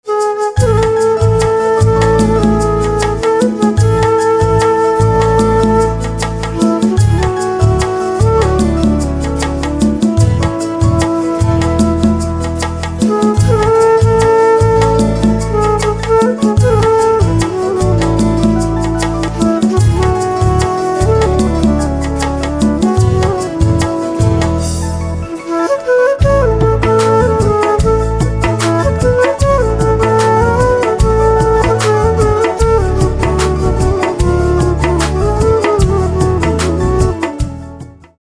2. Unique Flute Instrumental Ringtone Download.
It gives a natural and relaxing vibe.